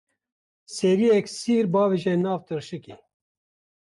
Pronounced as (IPA) /siːɾ/